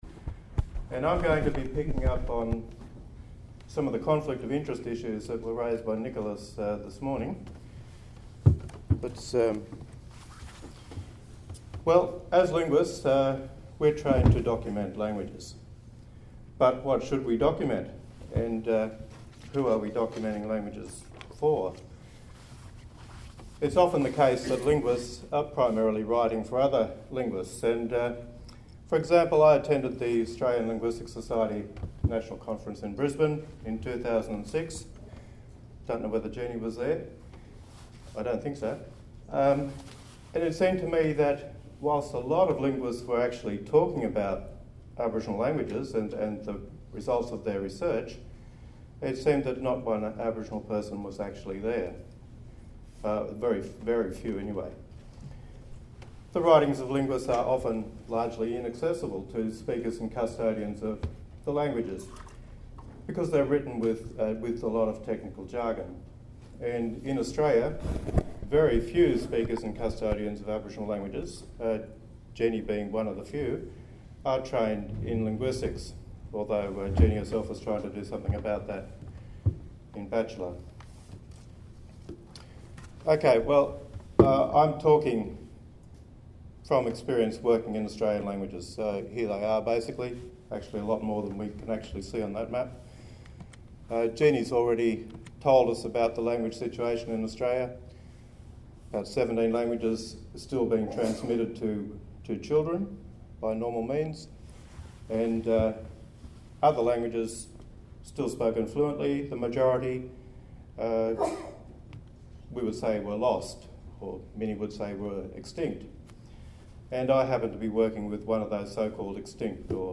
1st International Conference on Language Documentation and Conservation (ICLDC)